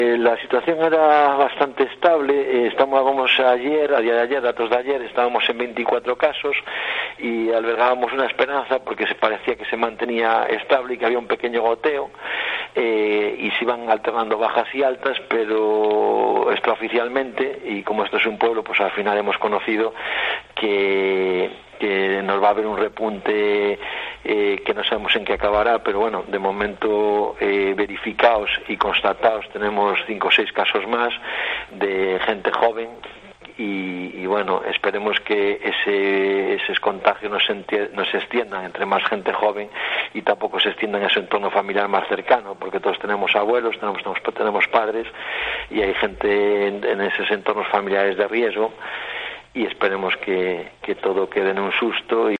Declaraciones del alcalde de A Rúa, Álvaro Fernández, sobre el nuevo brote de COVID-9 en su concello